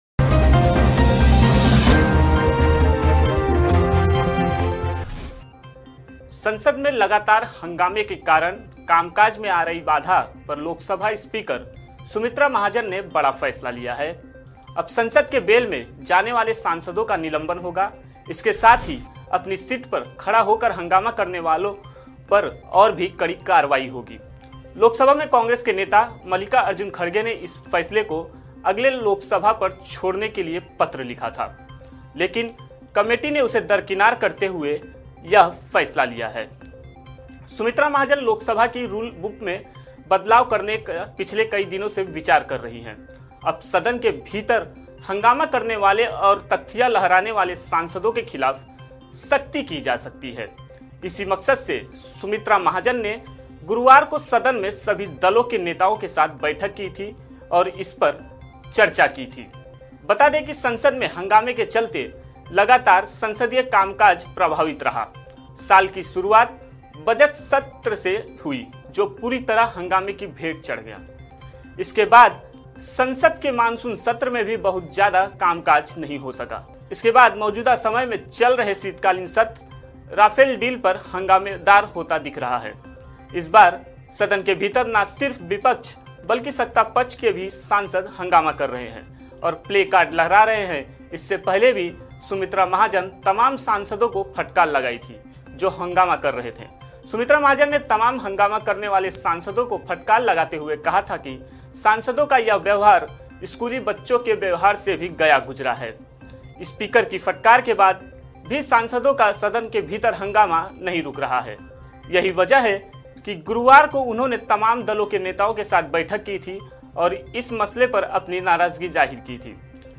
न्यूज़ रिपोर्ट - News Report Hindi / लोकसभा में हंगामा करना अब सांसदों को पड़ेगा भारी, मिलेगी यह सजा !